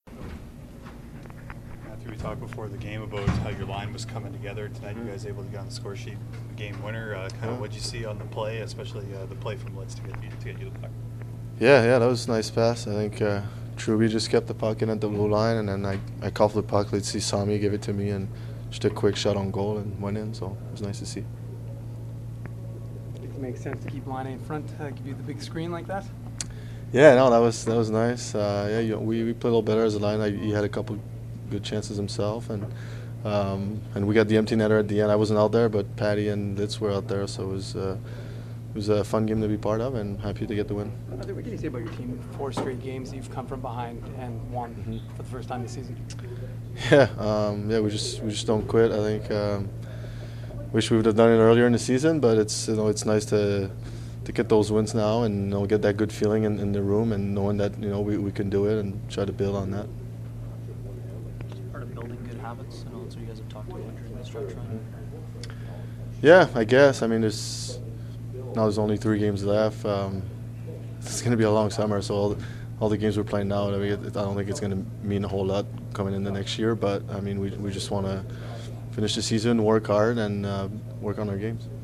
Post-game from the Jets dressing room as well as from Coach Maurice.
April-1-2017-Mathieu-Perreault-post-game.mp3